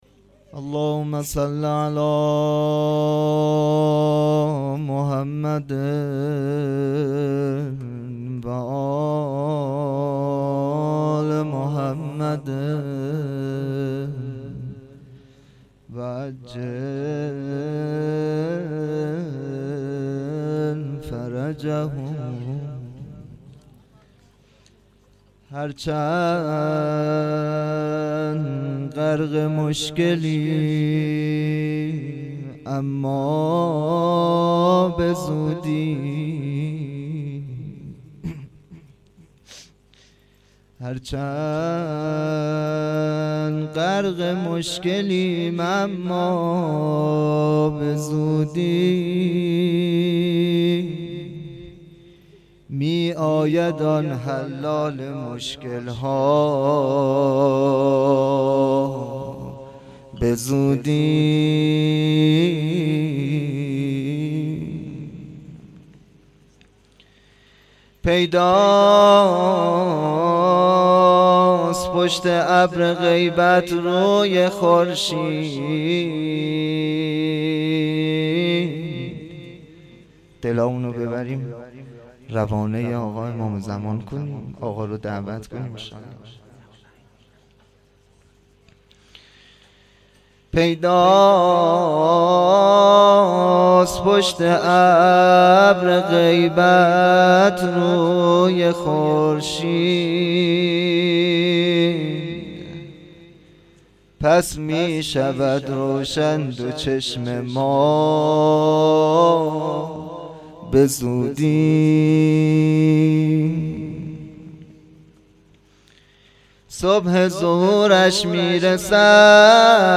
شهادت امام جواد علیه السلام